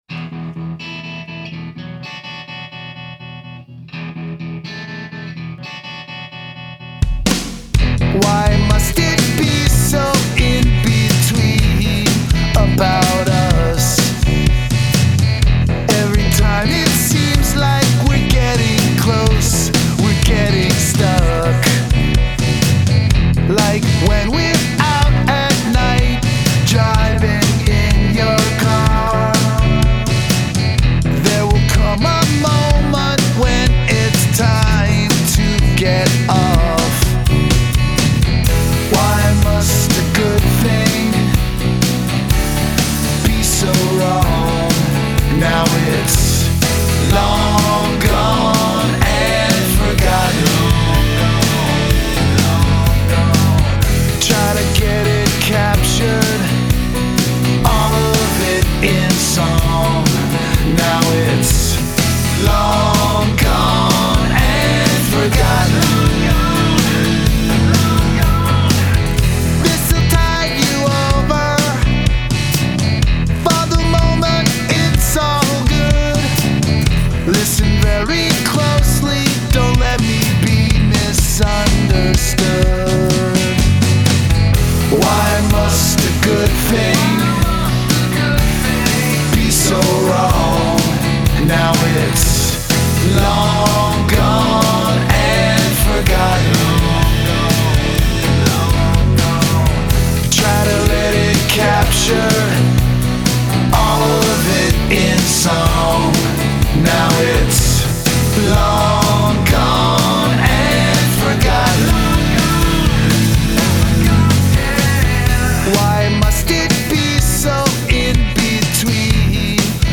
and particularly the wonderfully lush single